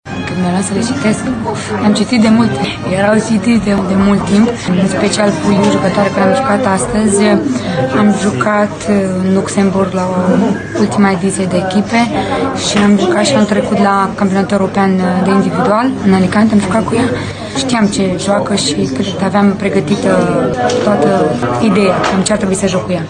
Întrebată când a citit jocul portughezelor, Dodean a afirmat că își cunoștea foarte bine adversara: